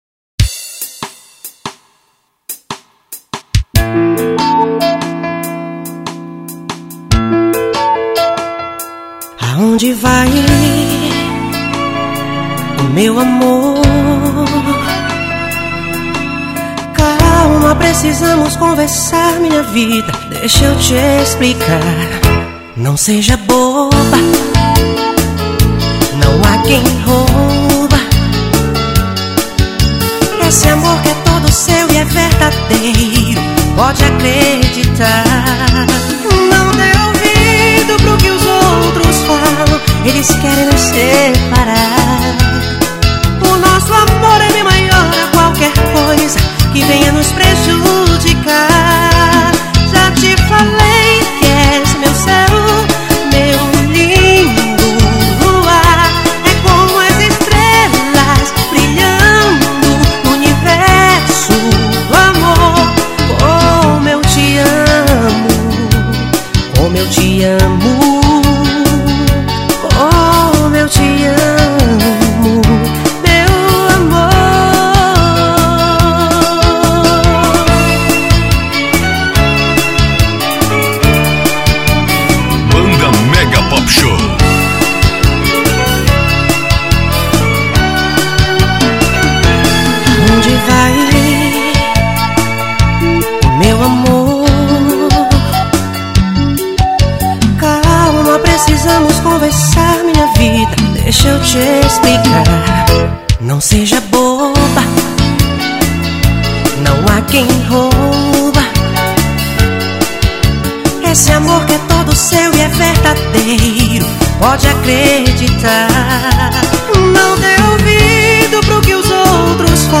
OUÇA NO YOUTUBE Labels: Arrocha Facebook Twitter